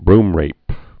(brmrāp, brm-)